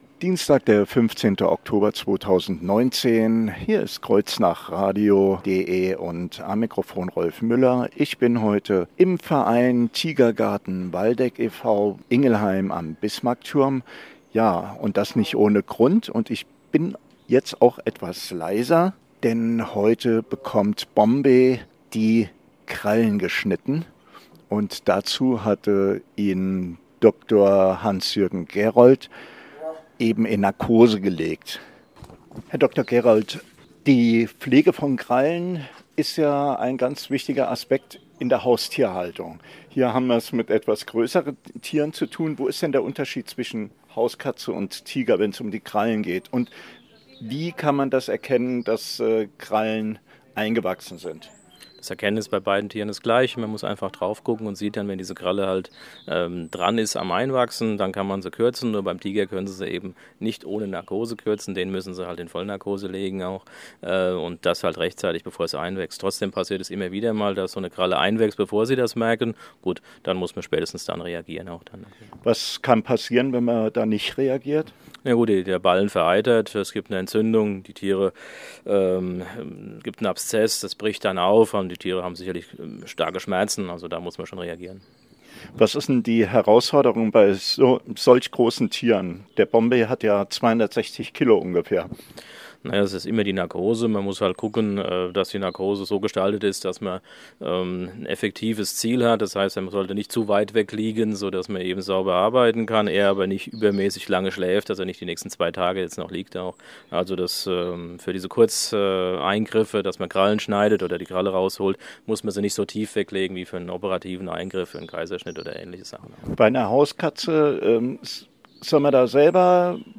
>>> Audio-Interview